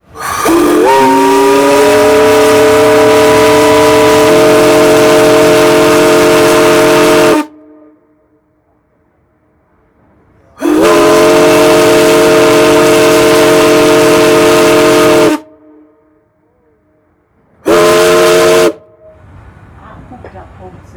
Die Geräusche aus den Regionen Lausanne und Alpes vaudoises, haben Sie bestimmt alle erraten.
B) Kursschiff
dampfschiff.wav